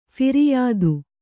♪ phiriyādu